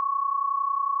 Listen to 1121 Hz 100% plus 1090 Hz 70%